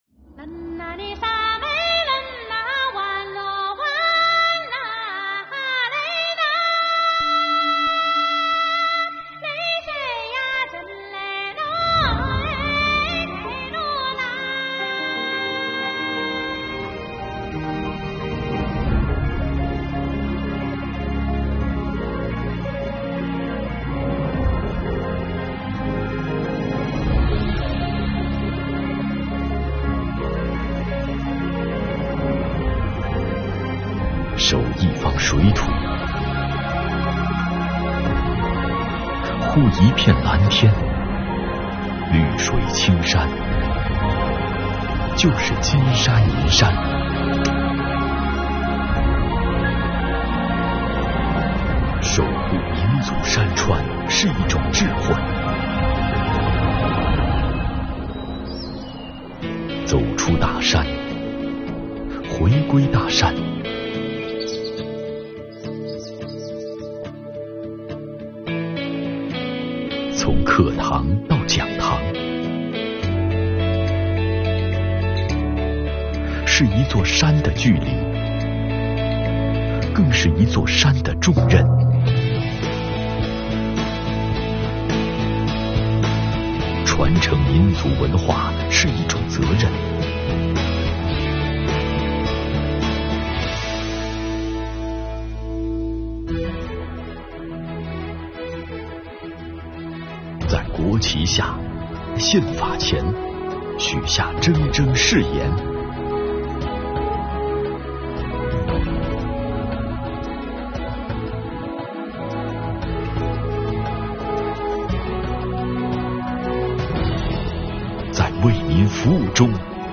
公益广告 | 以宪为纲 成就更好的我们